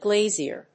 /gléɪʒɚ(米国英語), gléziə(英国英語)/